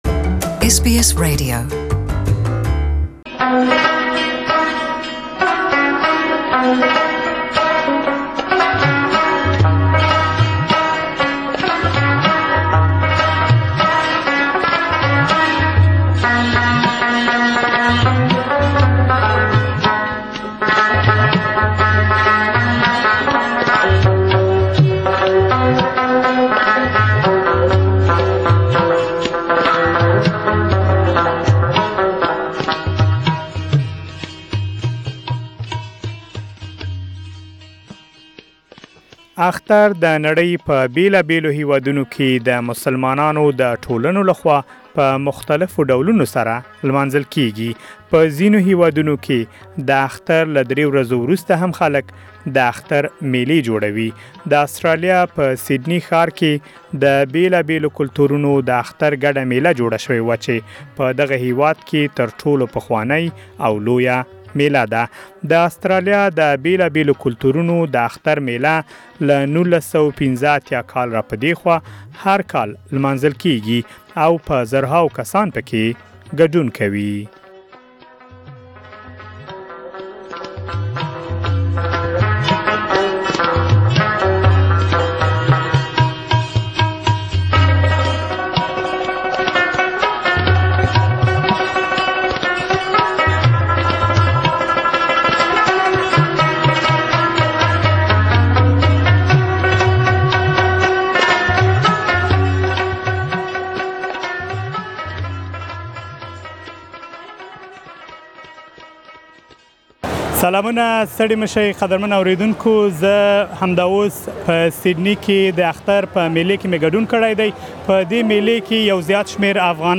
SBS Pashto also attended this festival and conducted a number of interviews with participants of the festival.